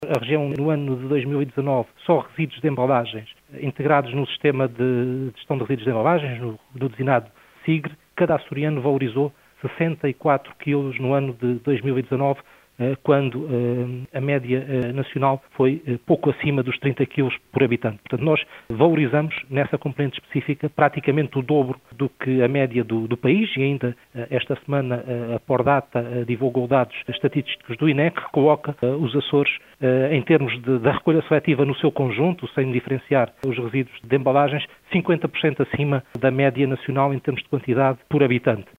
Hernâni Jorge, que falava à Atlântida, em pleno Dia Mundial do Ambiente, sublinha que a Região lidera também, em termos nacionais, relativamente à valorização de resíduos de embalagem, oriundos da recolha seletiva.